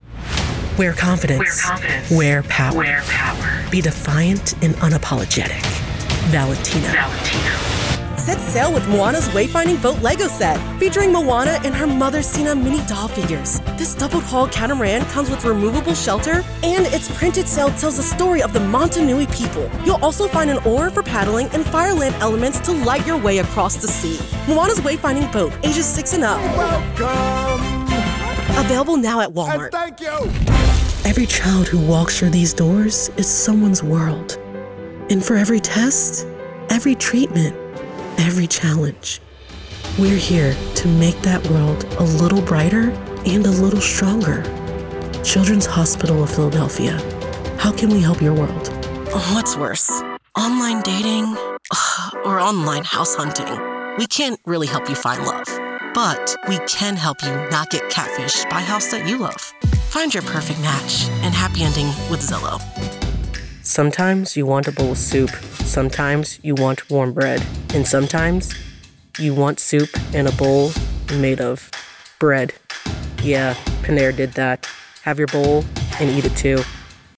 Commercial Demo Reel